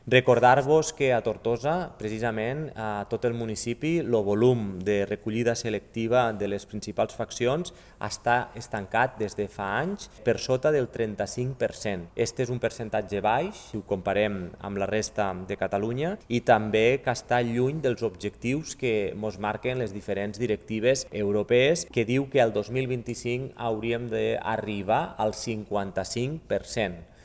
L’alcalde de Tortosa Jordi Jordan ha manifestat que este canvi el que representa és un canvi de model que ha de permetre aconseguir un increment del percentatge de reciclatge, i ha volgut insistir en el fet que es puga ampliar en diferents barris, pobles i emds de Tortosa. Jordan ha insistit que Tortosa manté un perfil baix, ja que està estancat per sota del 35% i lluny dels objectius que marquen les diferents directives europees.